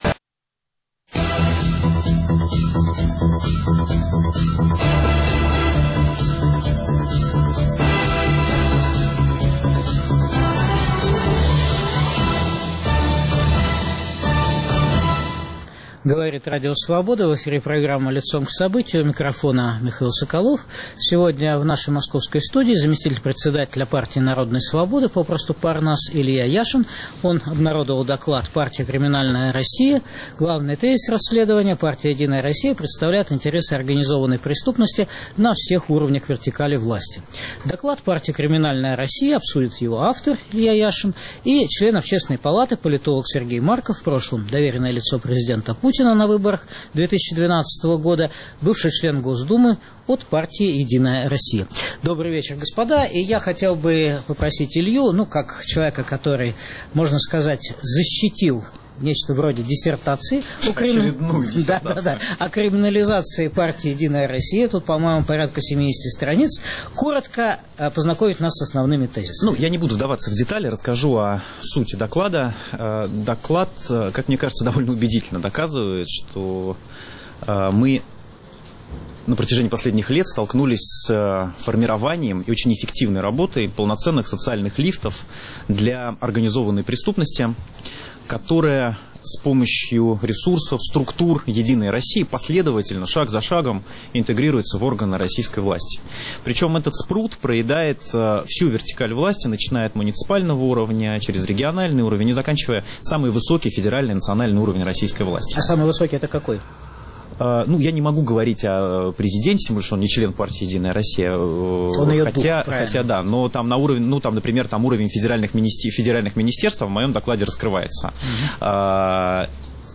Дискутируют Илья Яшин и Сергей Марков.